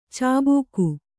♪ cābūku